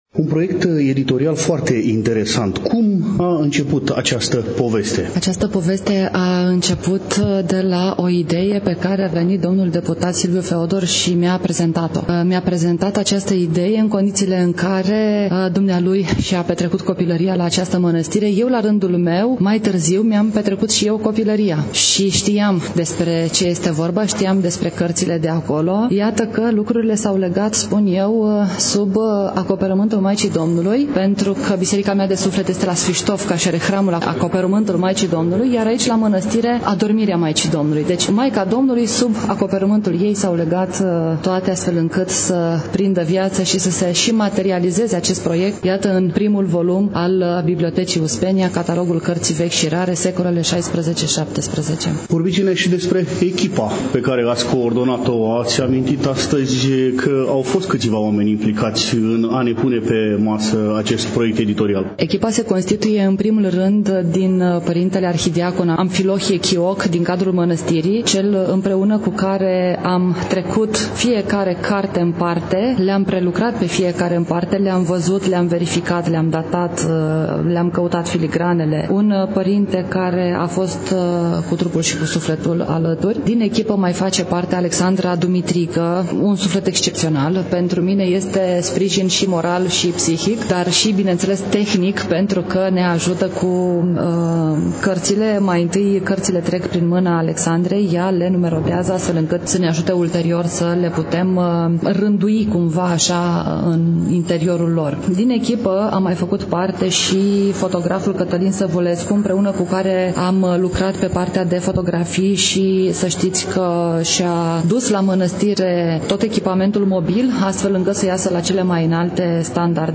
Evenimentul a avut loc, nu demult, în Amfiteatrul „I. H. Rădulescu” al Bibliotecii Academiei Române, București.